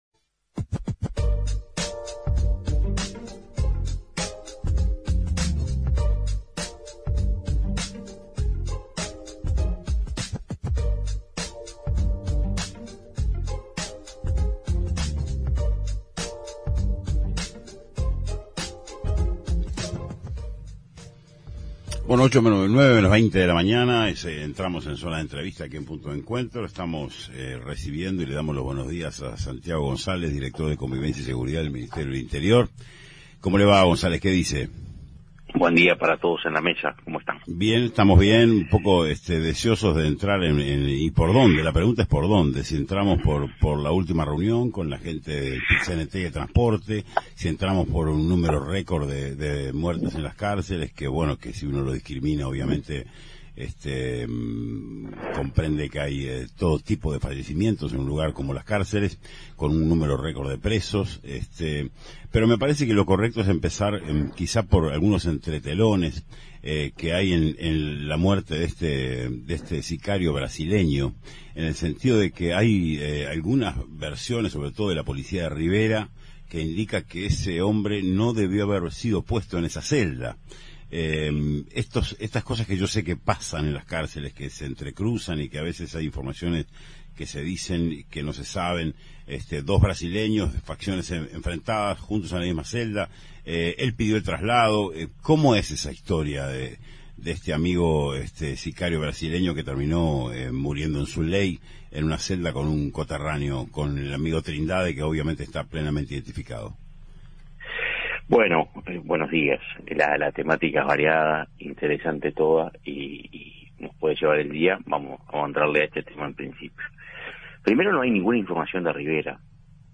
En entrevista en Punto de Encuentro el director de Convivencia y Seguridad Ciudadana del Ministerio del Interior, Santiago González, se refirió al recluso que fue asesinado por un compañero de celda en el Penal de Libertad.